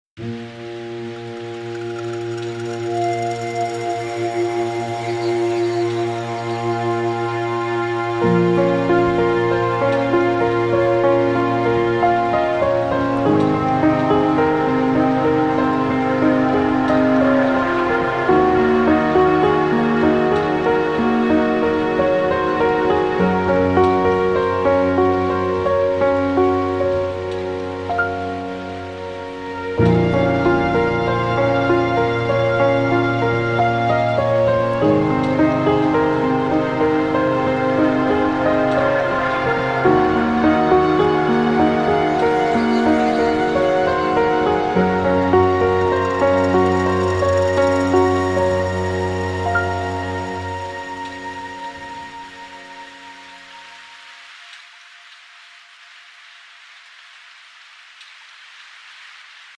It keeps a steady energy that makes every listen worthwhile.